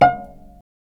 Index of /90_sSampleCDs/Roland - String Master Series/STR_Vcs Marc-Piz/STR_Vcs Pz.3 dry